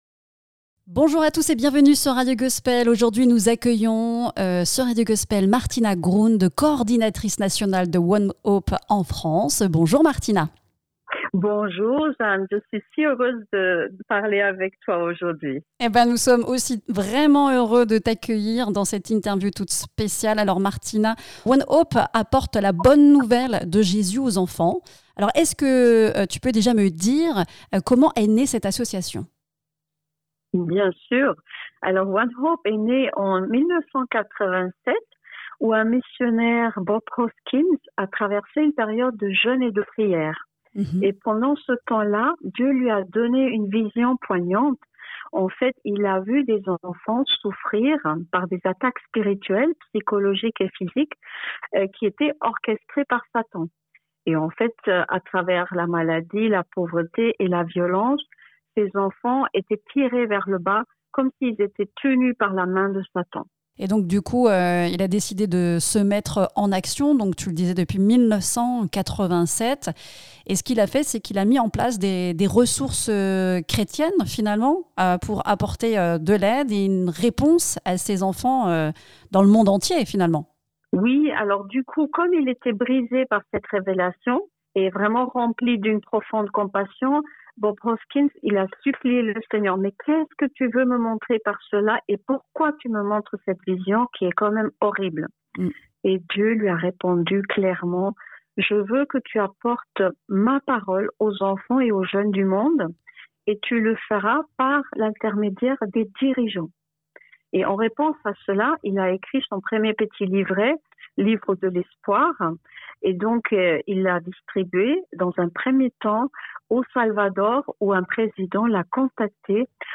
OneHope - Interview